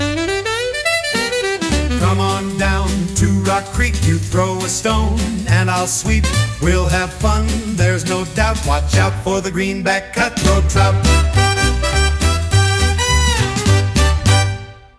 15-second radio jingle
With its infectious melody and memorable lyrics